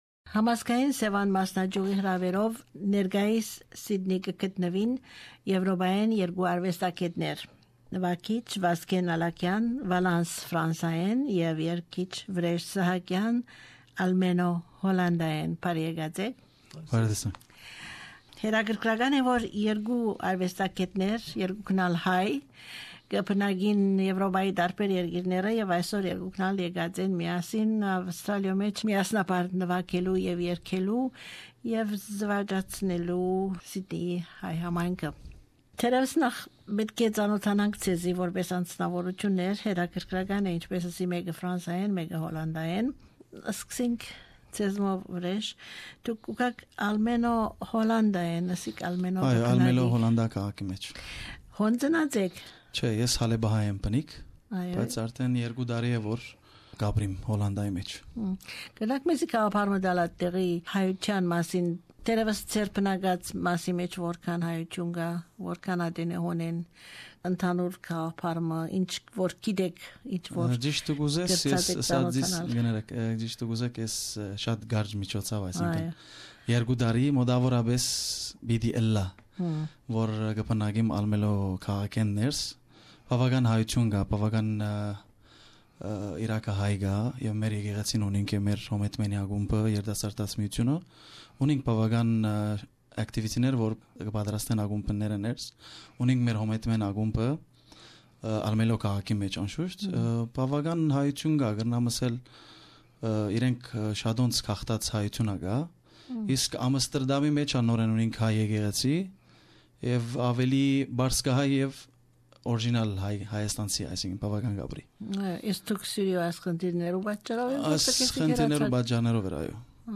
Interview with armenian artists from Holland and France.